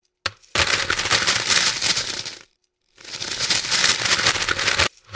Money_Reverse
Звук падающих монет задом наперёд: ожидания оправдались - как представлял себе это, так оно и оказалось (сыпал мелочь из плошки в тазик). Жаль только, что таз пластиковый, а не медный - звук не совсем тот!